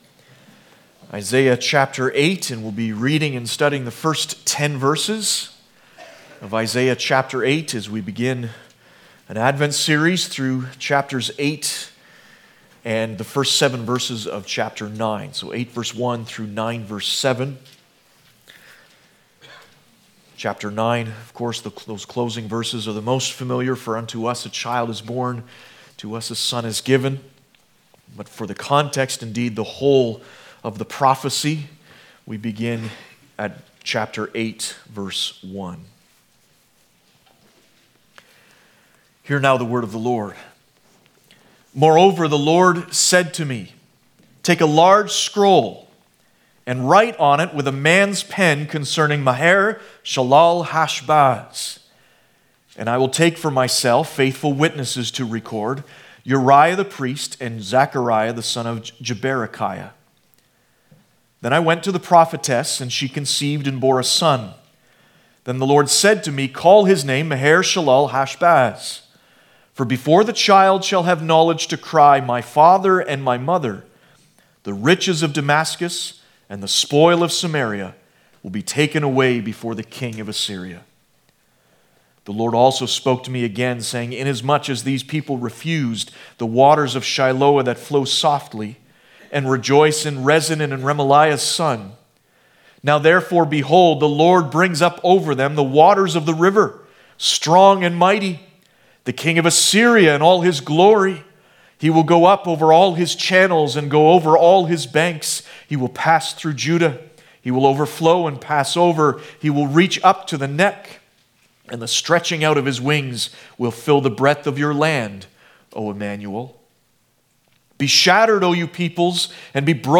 Advent Passage: Isaiah 8:1-10 Service Type: Sunday Morning « The Blessedness of the Tried Jesus said